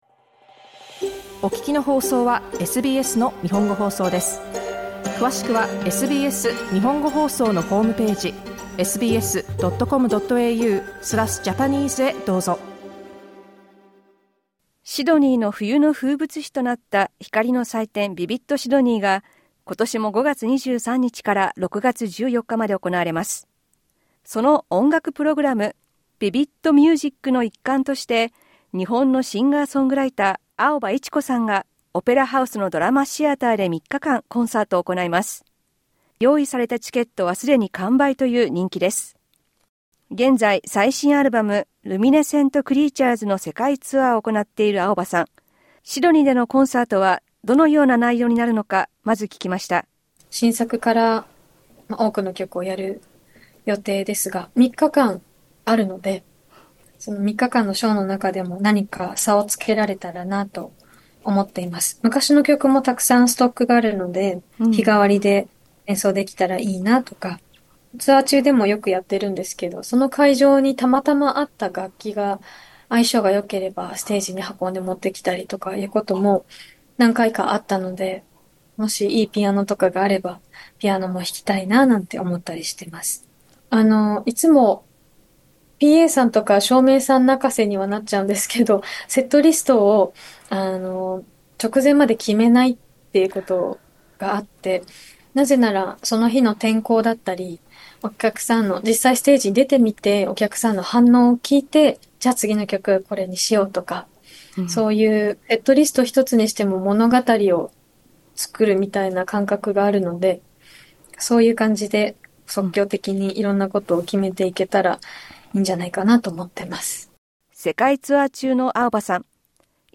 LISTEN TO 言葉を超えて共有する「音のふるえ」、シンガソングライター青葉市子 SBS Japanese 28/04/2025 12:22 Play インタビューでは、シドニー・オペラハウスでのコンサートや自身の創作の過程に、そしてAI（人工知能）などについて聞きました。